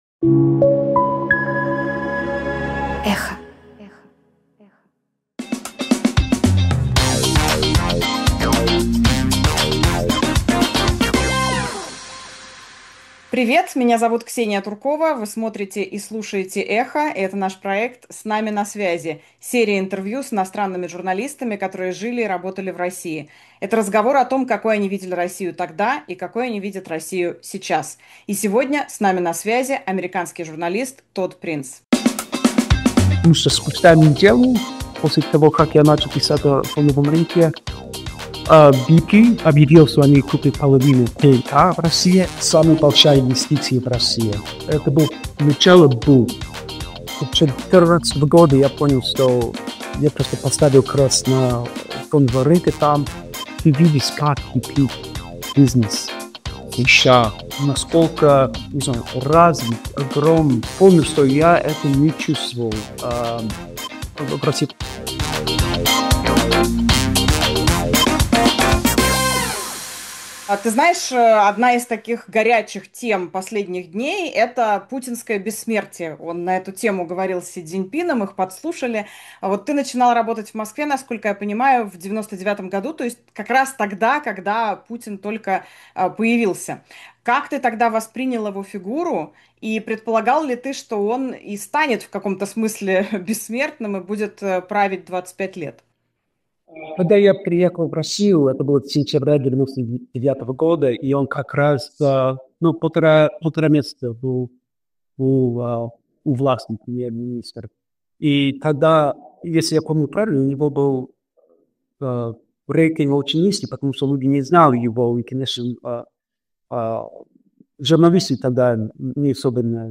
американский журналист